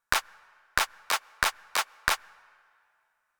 Here you will find rhythms with various types of notes in the bar.
One quarter note, two eighth notes, two eighth notes, and one quarter note.